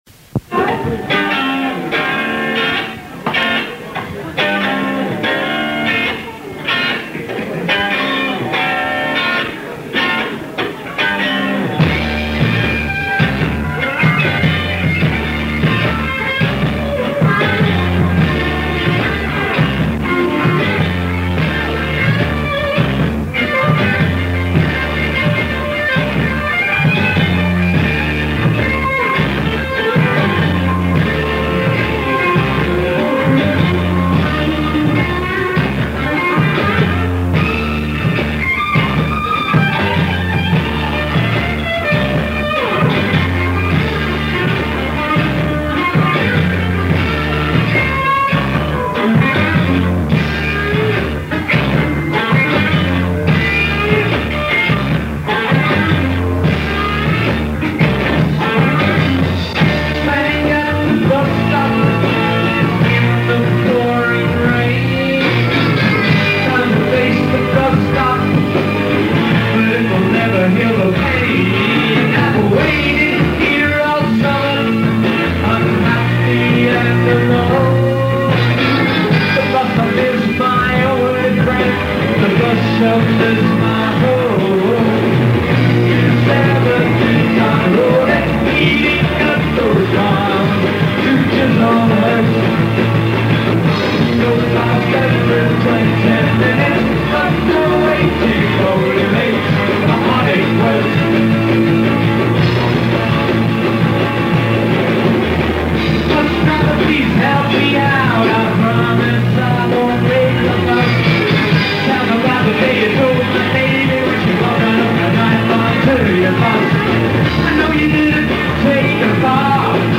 The Brecknock, Camden Rd,